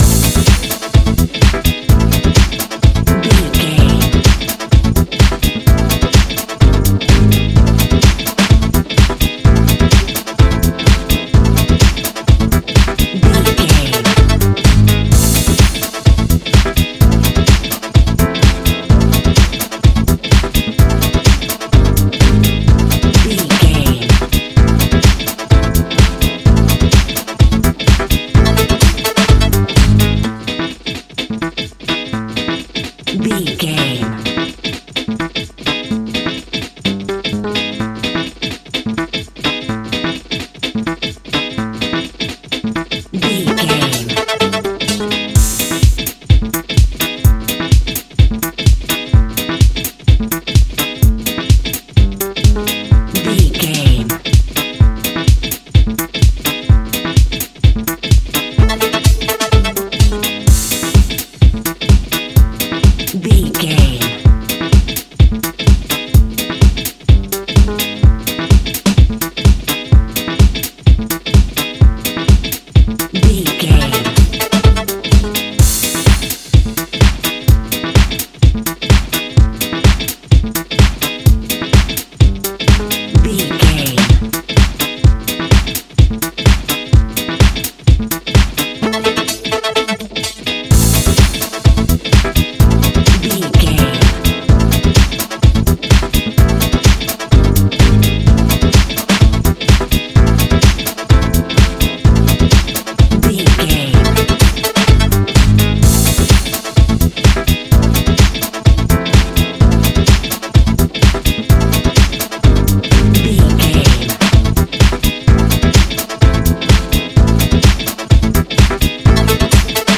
Aeolian/Minor
groovy
smooth
futuristic
uplifting
drum machine
synthesiser
bass guitar
electric piano
post disco
electro house
joyful